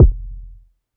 KICK - SKYFALL.wav